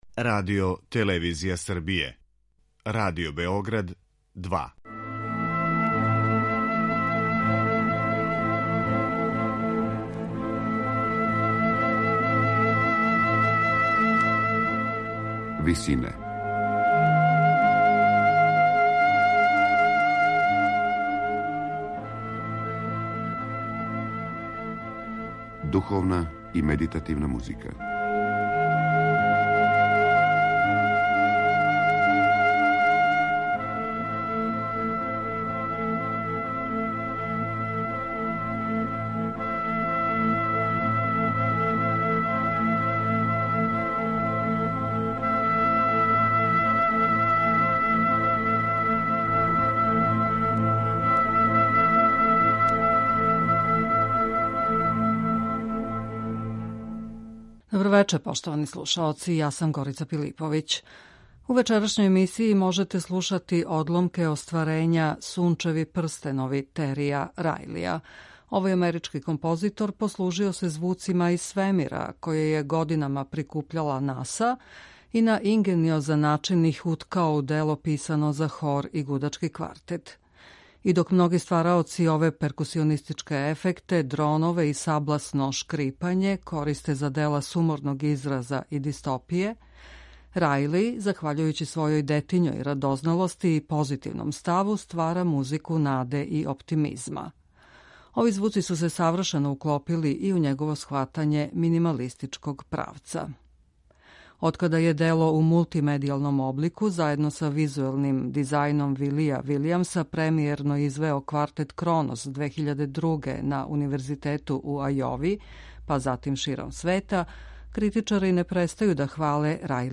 за хор и гудачки квартет
ствара музику наде и оптимизма.